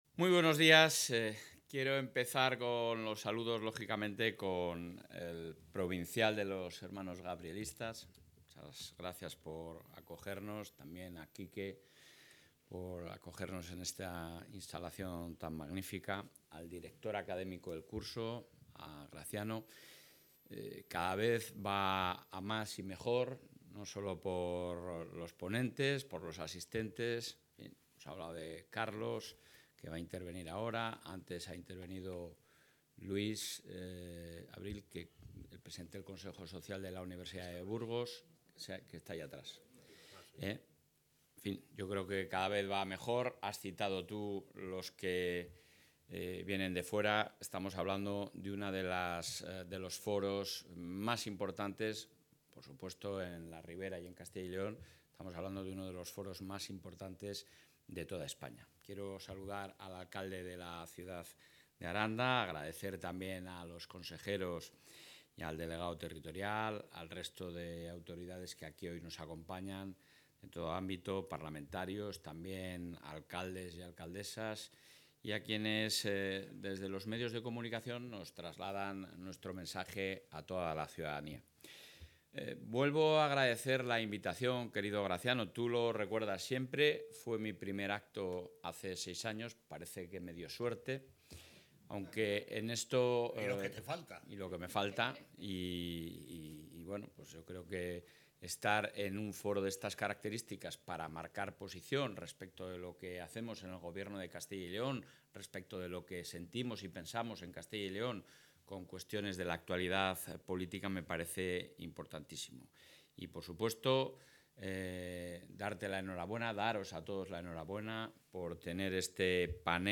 Durante su intervención en la XIII edición del curso universitario 'Prensa y Poder', bajo el título ‘La...
Intervención del presidente.